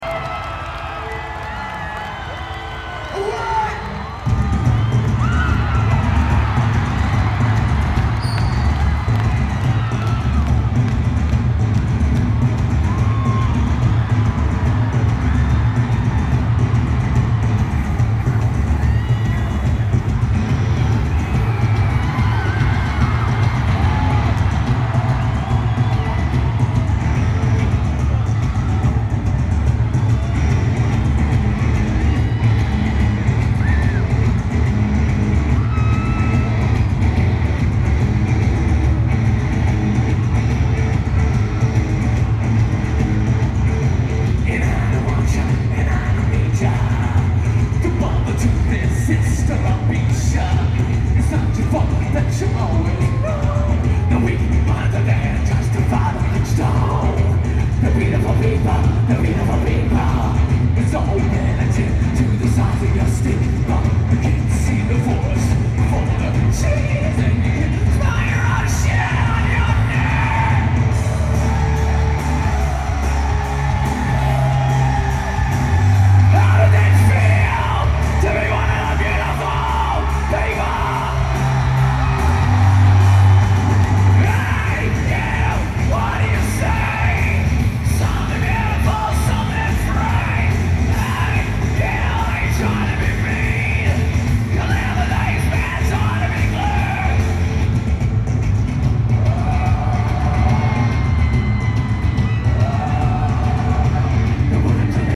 Madison Square Garden